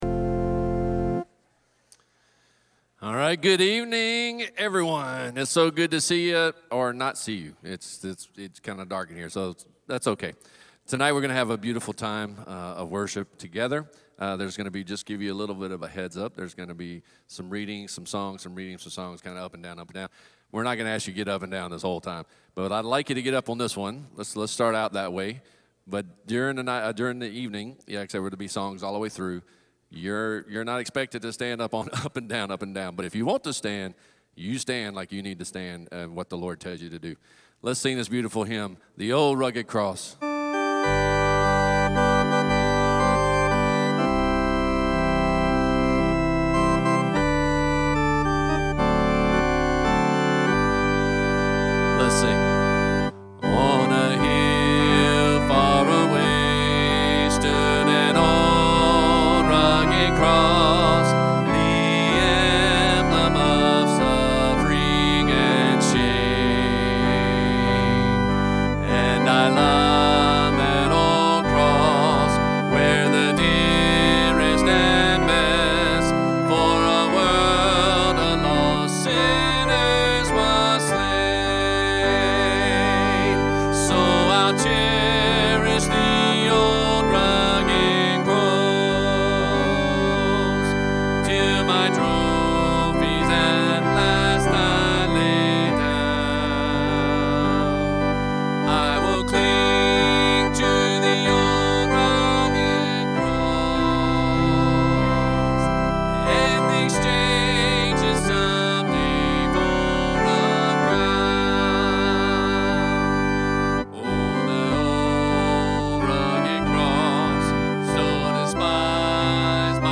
Good Friday Service | 4/18/25